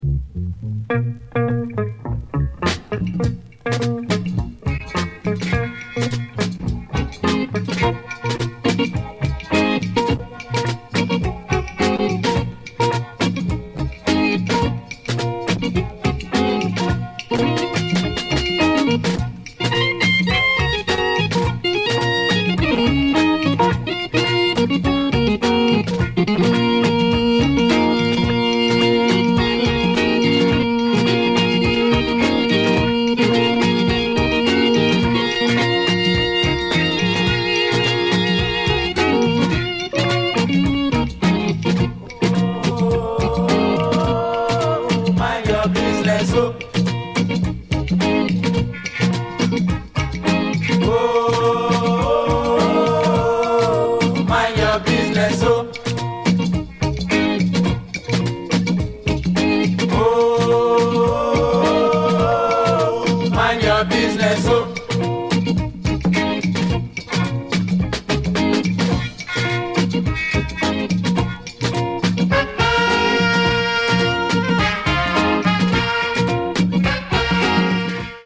Dope comp. of Afro-Psych shizzle.